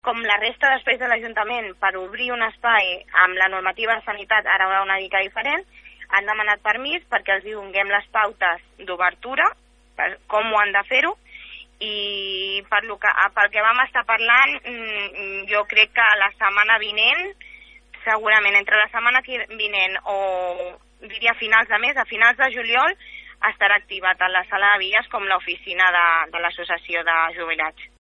Sònia Gonzàlez és regidora de Gent Gran de l’Ajuntament de Palafolls.